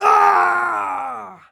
Male_Death_Shout_01.wav